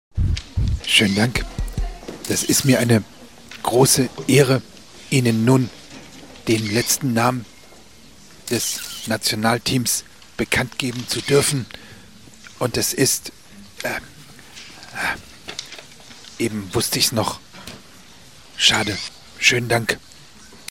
Wie es sich anhören würde, wenn Bundeskanzler Olaf Scholz einen weiteren Spieler präsentiert, haben sich die Kollegen von der SWR3 Comedy überlegt: